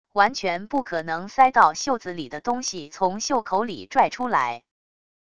完全不可能塞到袖子里的东西从袖口里拽出来wav音频